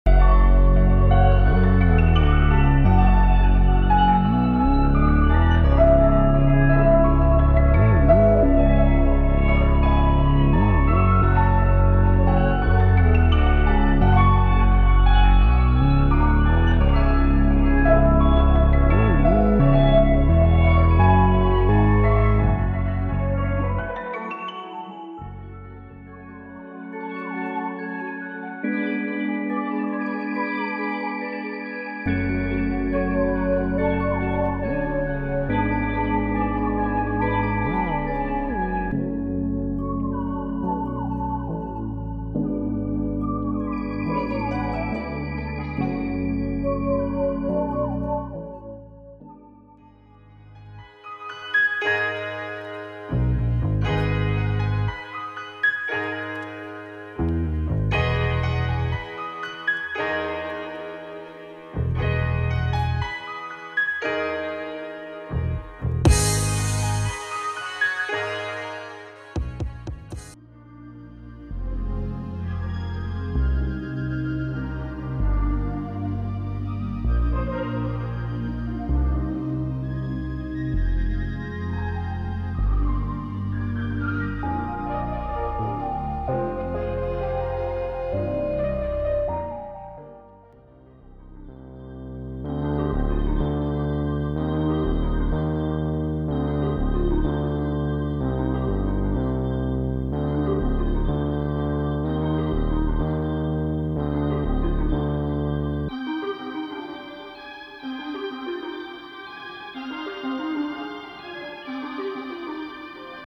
6 LoFi/Hip-Hop packs